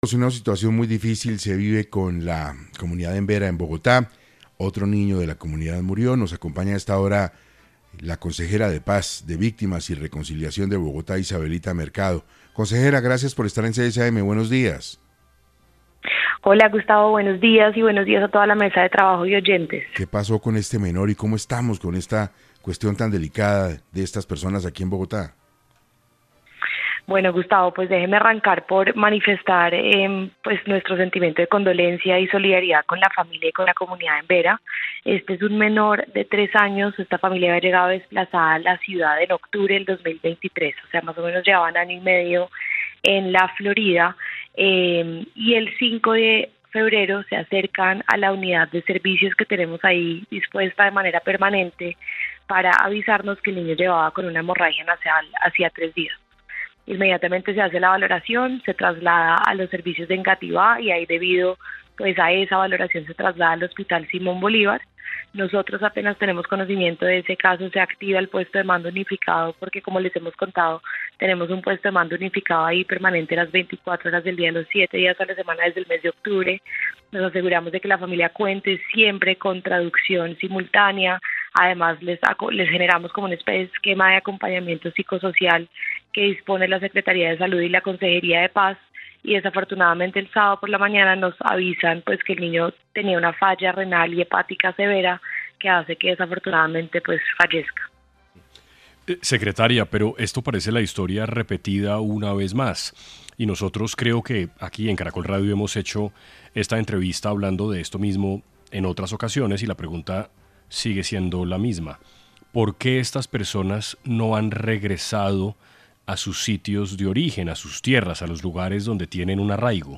Isabelita Mercado, consejera de Paz, Víctimas y Reconciliación de Bogotá aseguró en 6AM que las familias llevan esperando años en asentamientos transitorios.